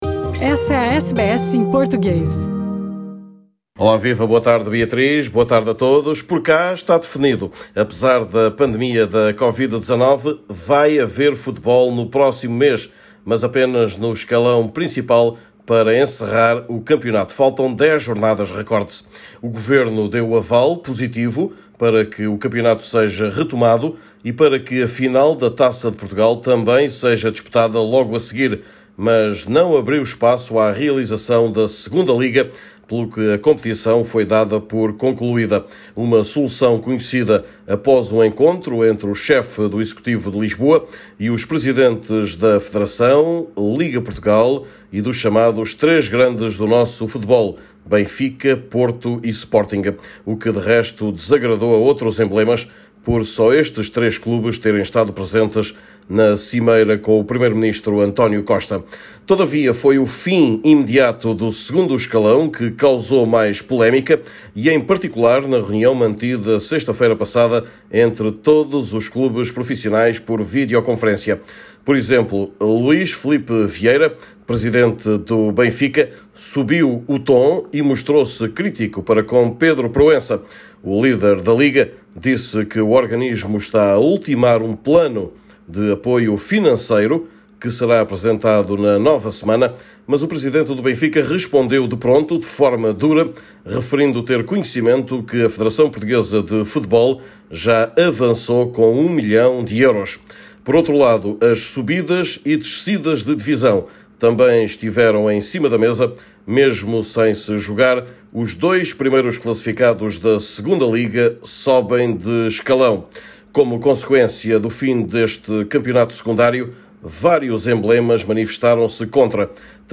Neste boletim semanal fala-se também de outros desportos cancelados: o Rali de Portugal, prova do mundial, não se correrá em 2020 e várias federações anularam as suas competições.